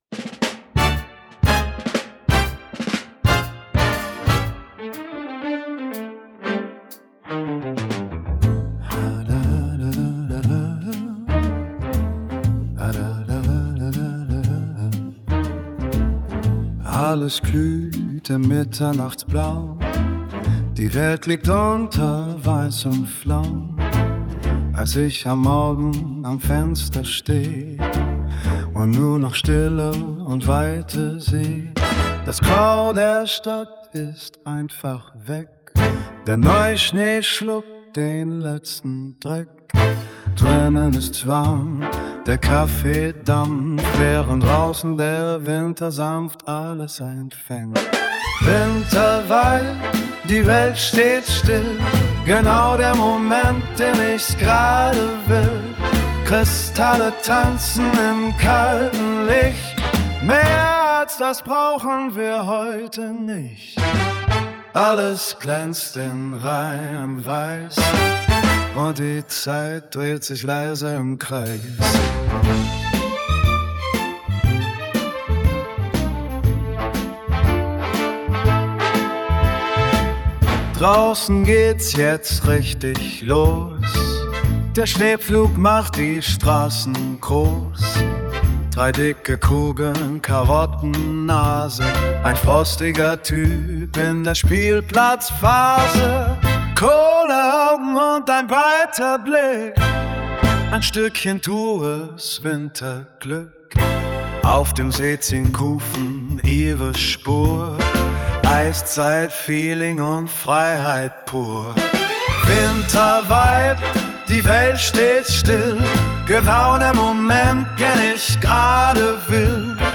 Song 2: Sänger, Jazz, Warm, Orchestral